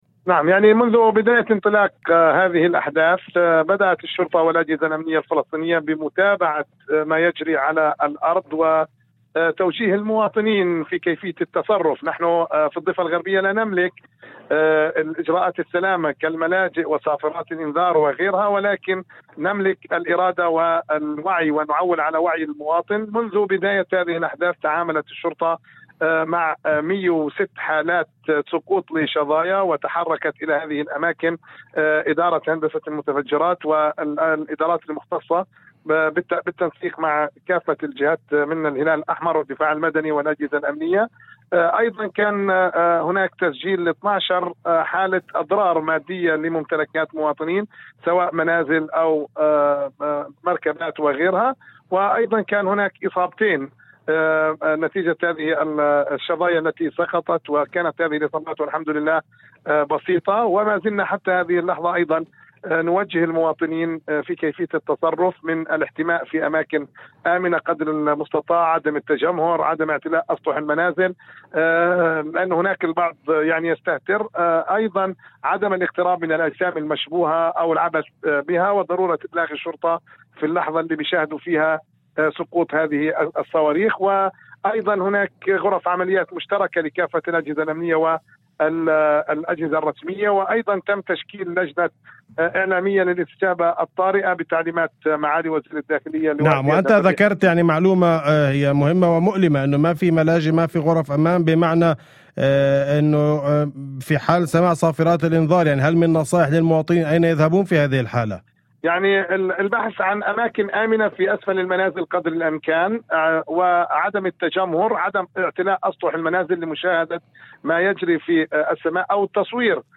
في مداخلة هاتفية لإذاعة الشمس